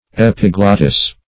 Epiglottis \Ep`i*glot"tis\, n. [NL., fr. Gr.